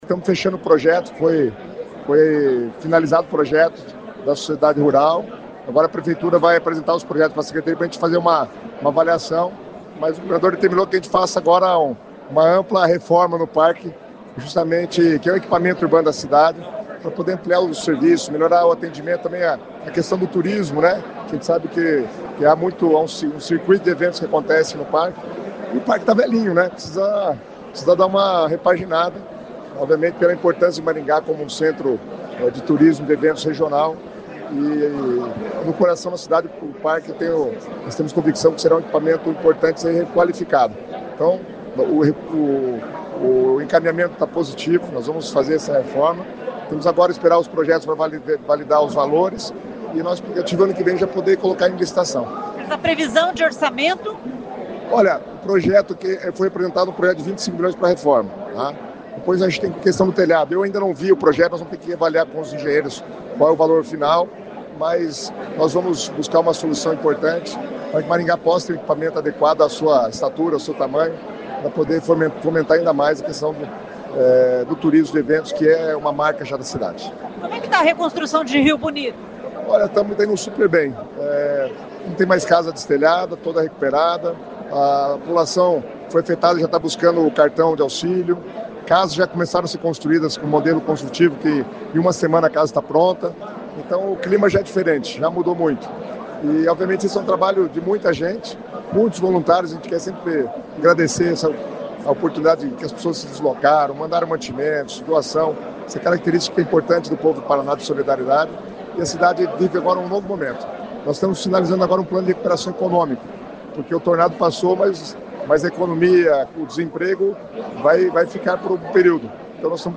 Ouça a entrevista com Guto Silva: